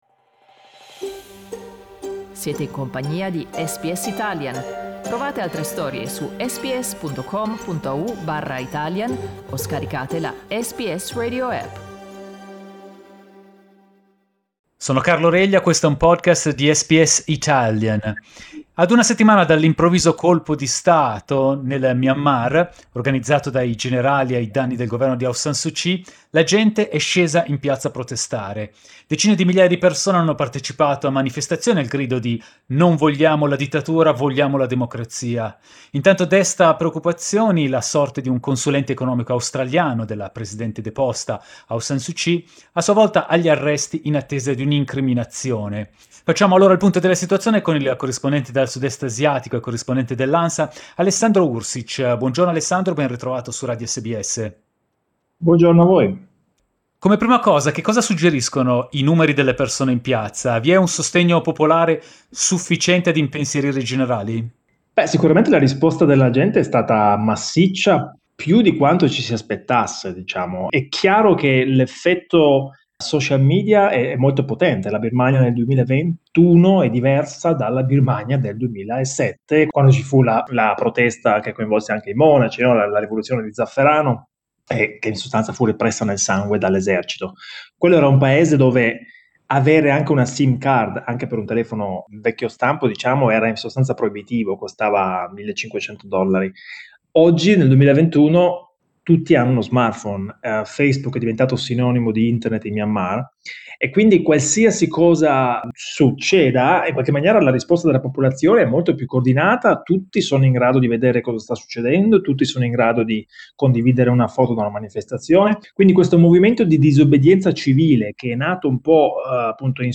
Il corrispondente dal sud-est asiatico